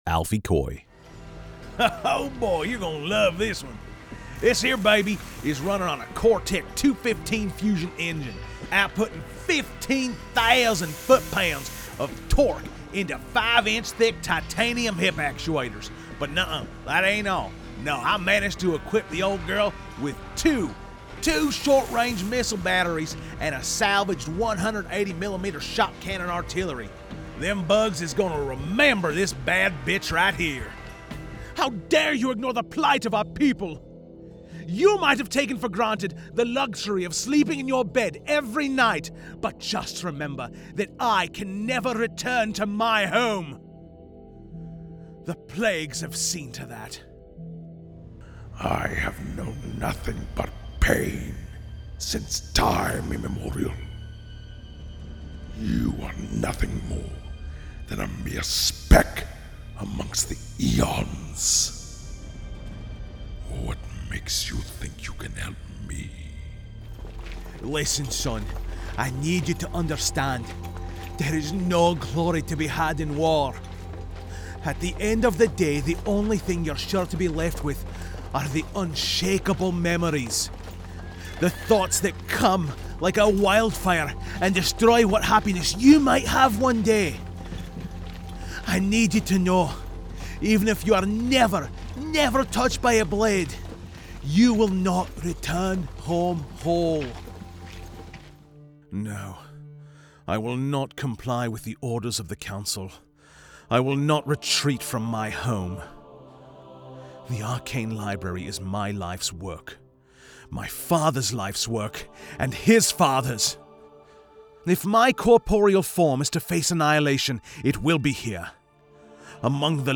Friendly, energetic, familiar, and funny. Director tested. Client approved.
Nondescript American • New York City • Coastal/Lowland Southern • Upper Midwestern • Midland American • Western American
Young Adult
My strong suits include the casual and conversational reads that sound like they're coming from your best friend, the enthusiastic and excited reads that hype up audiences, and well-honed comedic timing for your sharp-witted copy that comes across effortlessly.